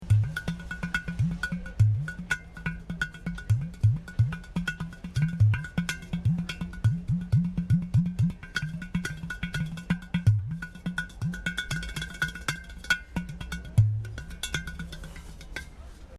A rgilophones pentatoniques avec monorésonateur
pouvant aussi être utilisé comme un Udu (Potée, Gatham)
gros udu.mp3